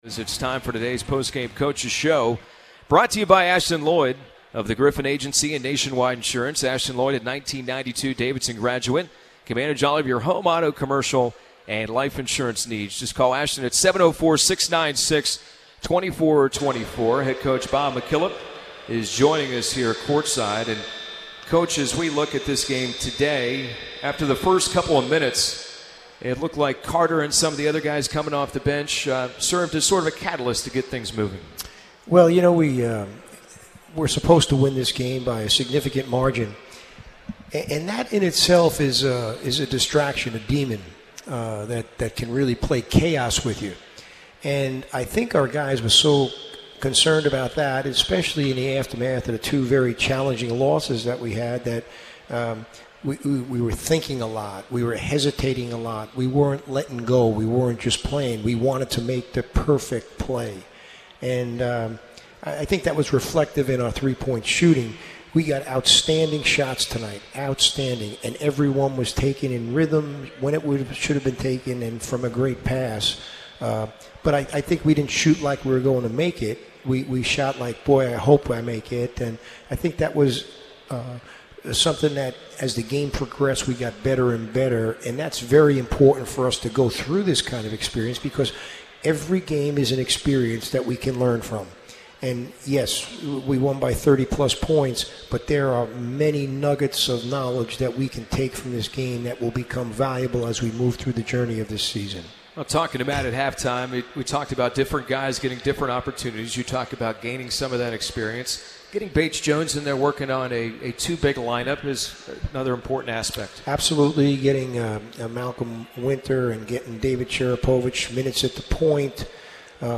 McKillop Postgame Interview